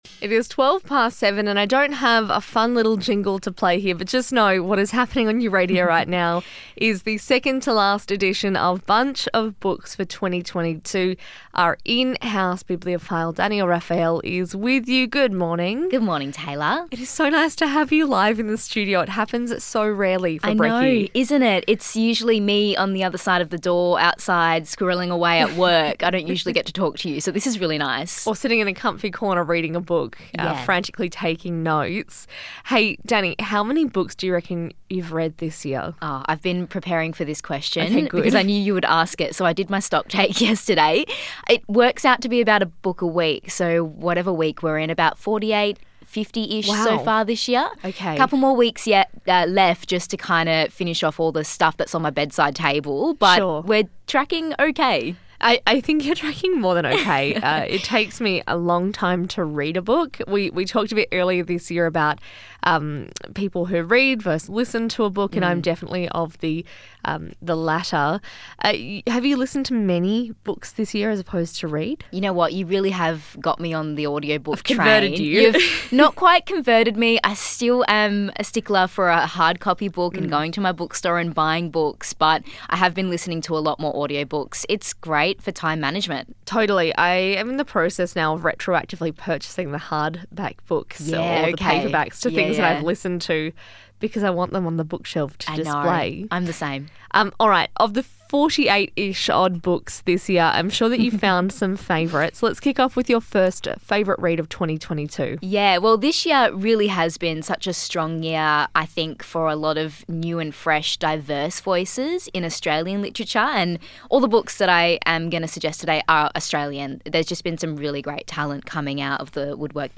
hang out in the studio to share their top reads from 2022.